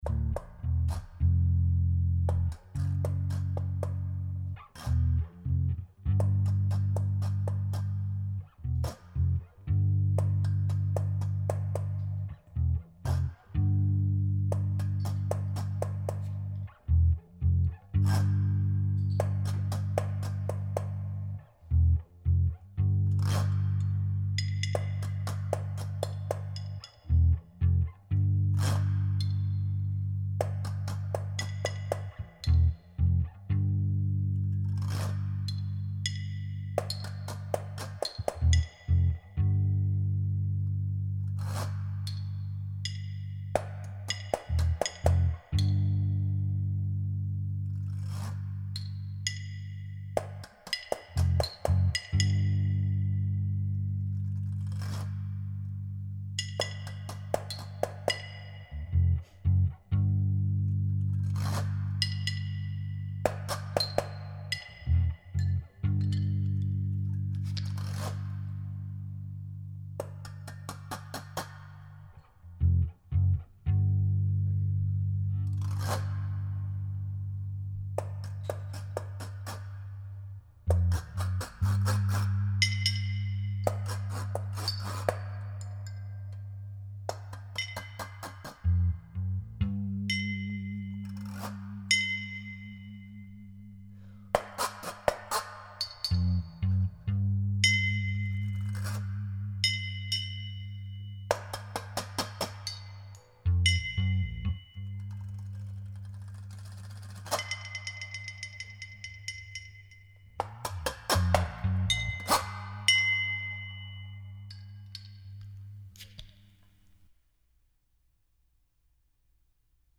Recorded live at the Maid’s Room, NYC January 7, 2009
tenor sax, beer bottles
washboard